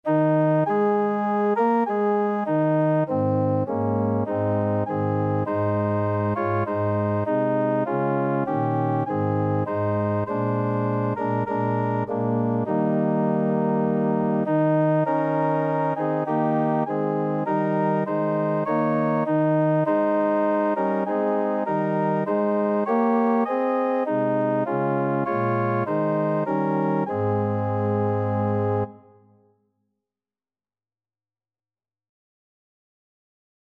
4/4 (View more 4/4 Music)
Organ  (View more Intermediate Organ Music)
Classical (View more Classical Organ Music)